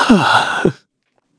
Shakmeh-Vox_Sad_kr_a.wav